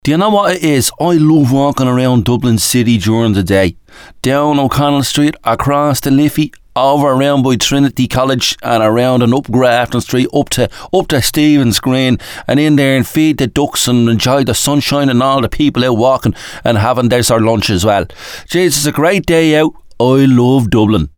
DUBLIN-ACCENT-I-LOVE-DUBLIN.mp3